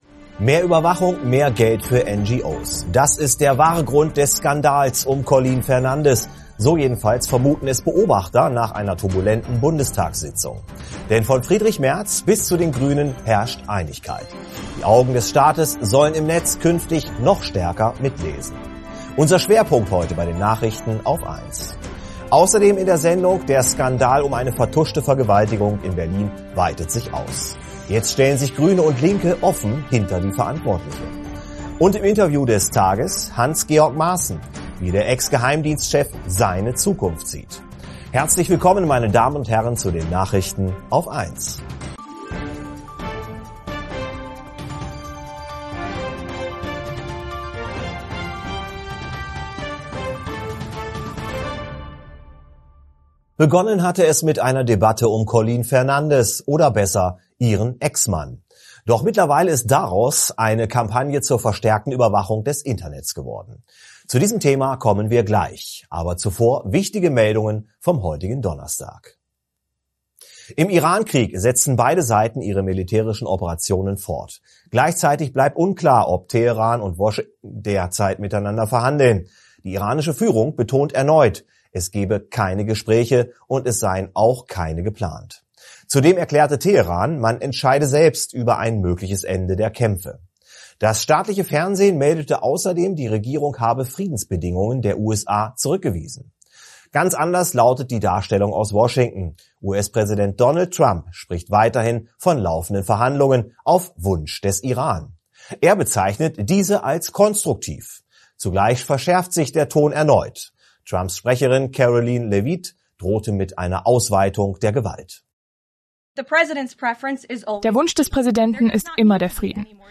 + Und im Interview des Tages: Hans-Georg Maaßen.